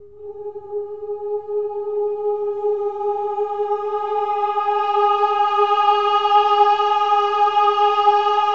OH-AH  G#4-R.wav